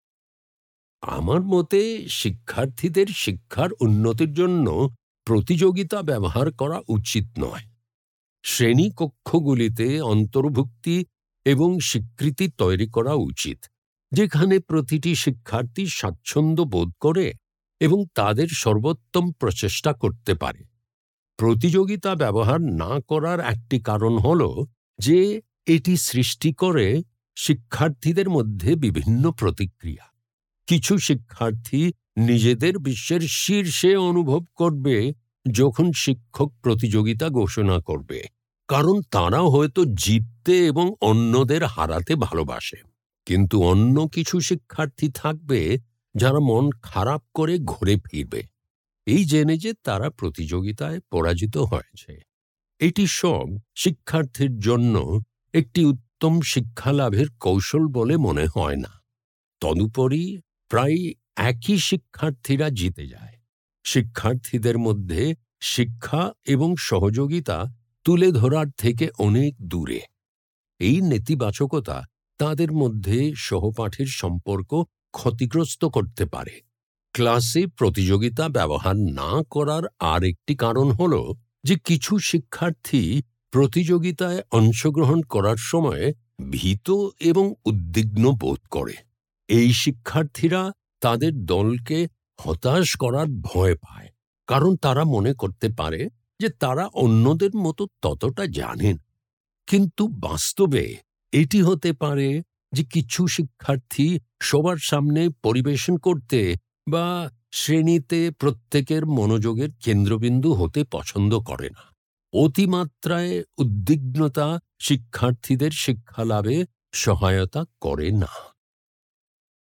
Presentational Speaking: Bengali
[Note: In the transcript below, ellipses indicate that the speaker paused.]